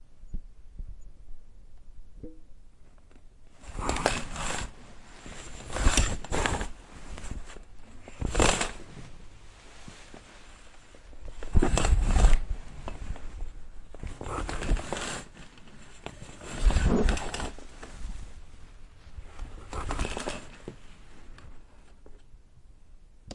窗帘的开启和关闭 OWI
描述：使用步枪麦克风录制。窗帘打开和关闭